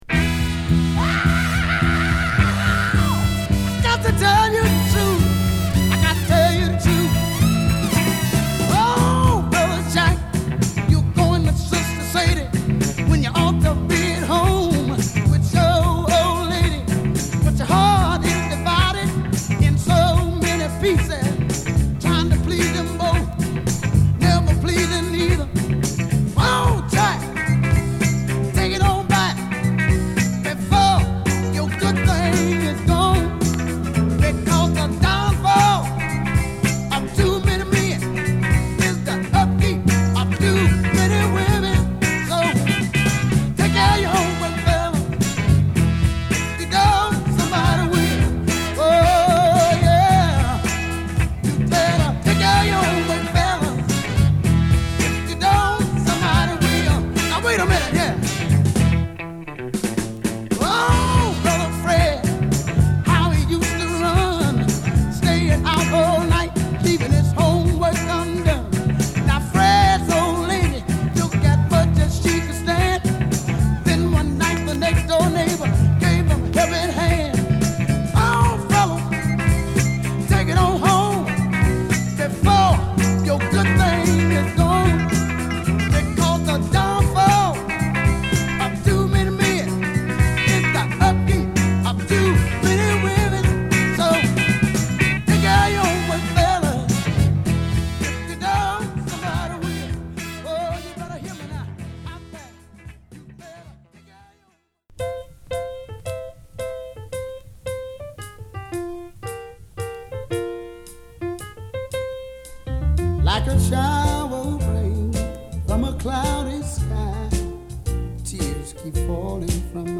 ＊チリパチ出ます。